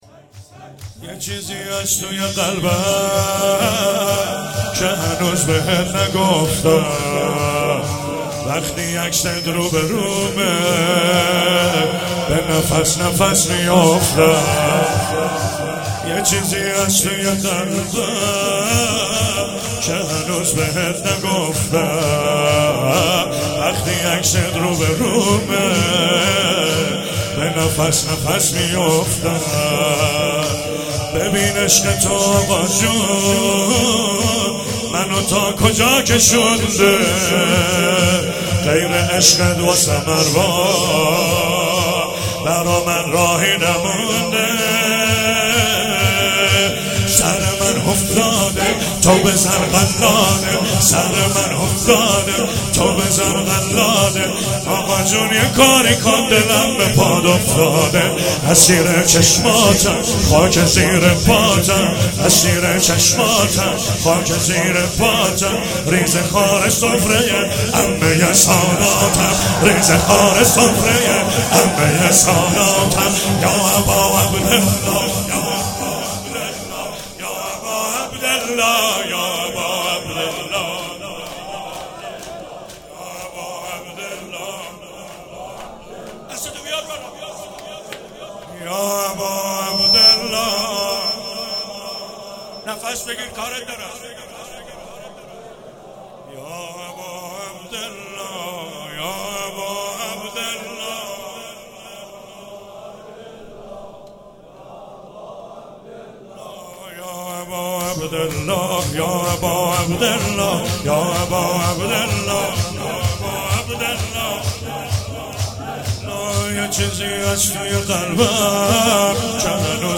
شب دوم محرم 95